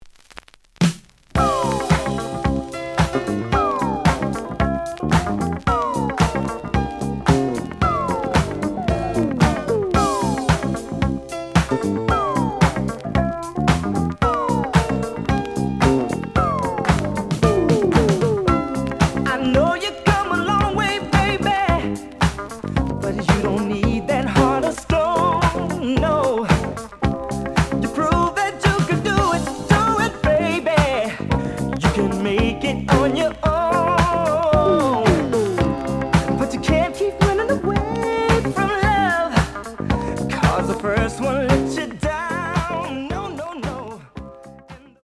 The audio sample is recorded from the actual item.
●Genre: Disco
●Record Grading: VG (傷は多いが、プレイはまずまず。Plays good.)